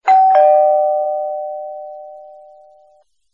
Ringtone Doorbell